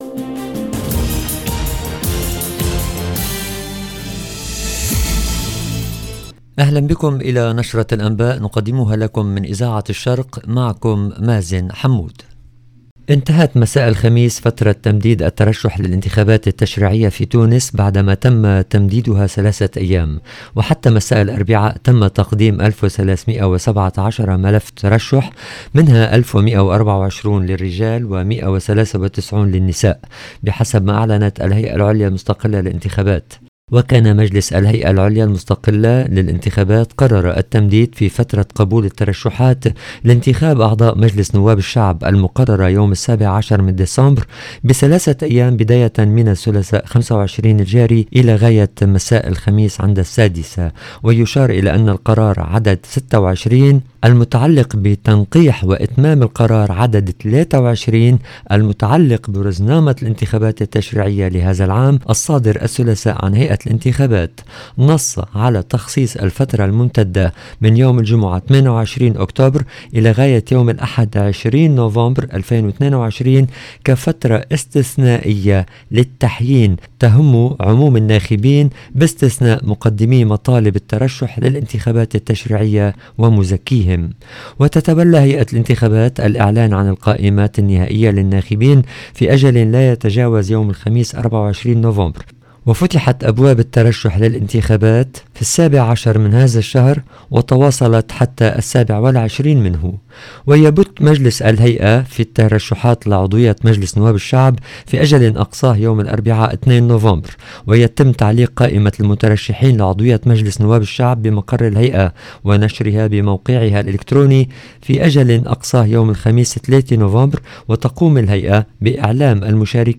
LE JOURNAL DU SOIR EN LANGUE ARABE DU 27/10/22